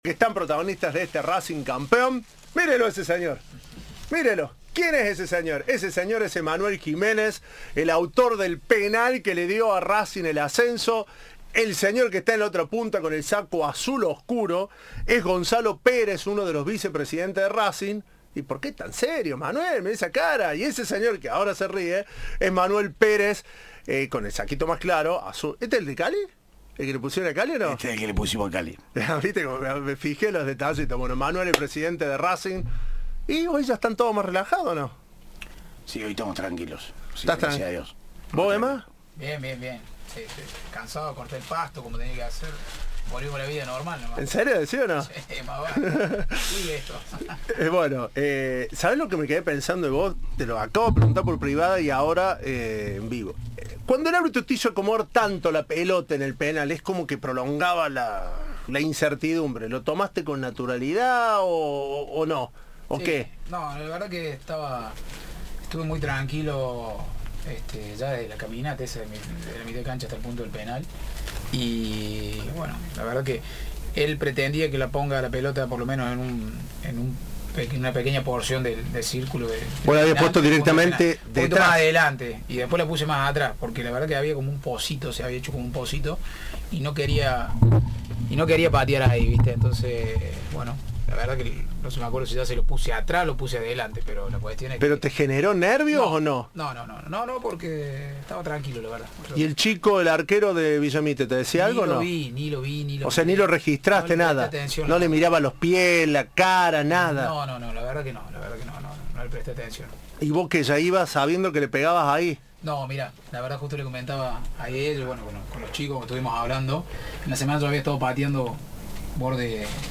Racing de Córdoba, en los estudios de Cadena 3 tras el inolvidable campeonato